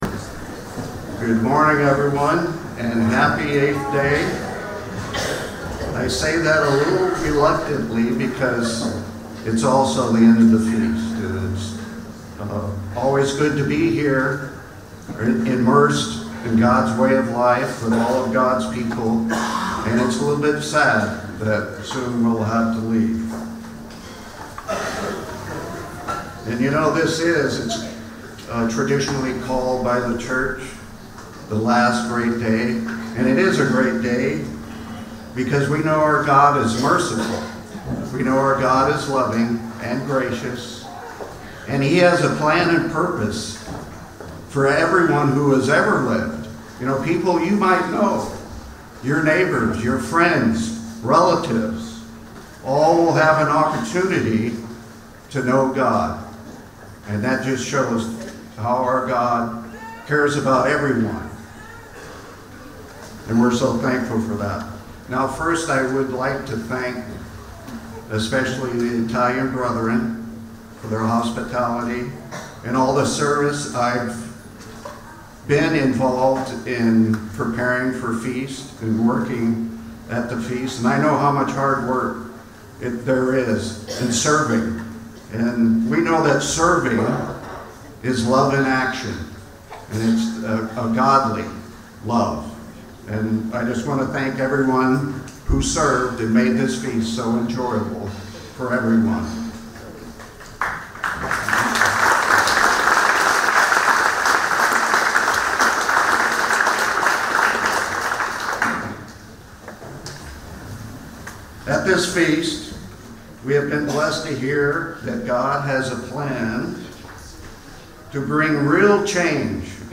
LGD 2024 Marina di Grosseto (Italy): Morning Service